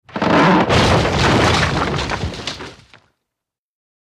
IMPACTS & CRASHES - VARIOUS WOOD & STONE: EXT: Small collapse with wood debris, light stone impacts.